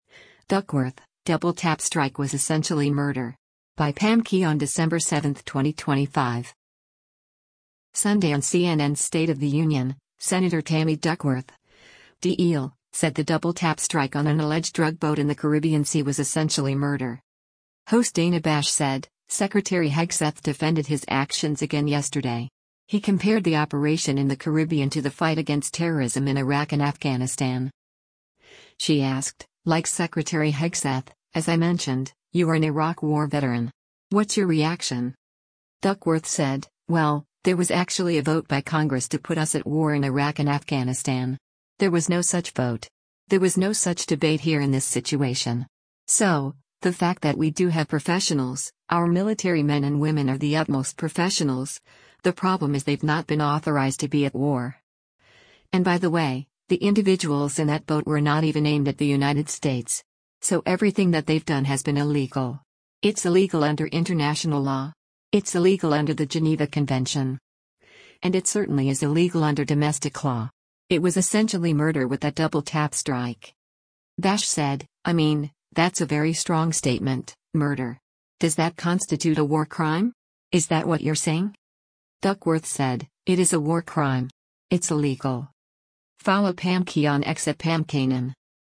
Sunday on CNN’s “State of the Union,” Sen. Tammy Duckworth (D-IL) said the “double-tap strike” on an alleged drug boat in the Caribbean Sea was “essentially murder.”
Host Dana Bash said, “Secretary Hegseth defended his actions again yesterday. He compared the operation in the Caribbean to the fight against terrorism in Iraq and Afghanistan.”